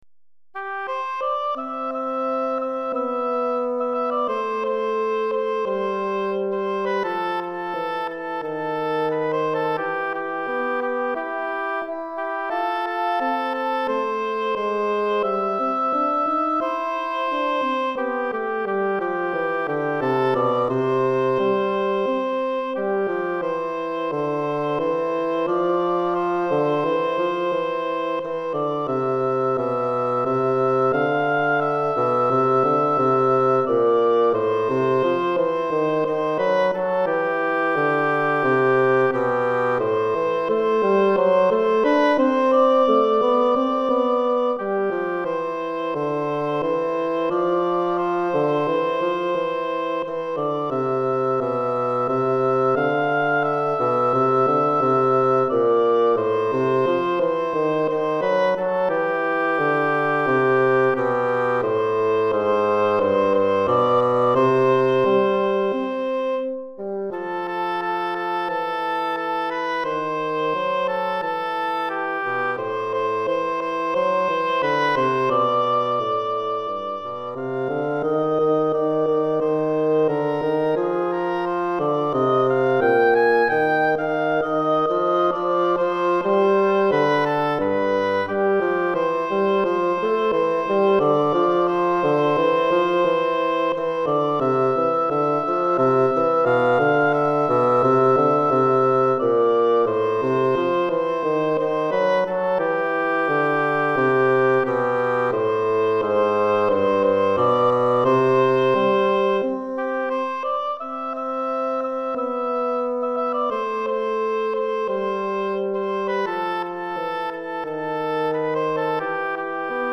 Hautbois et Basson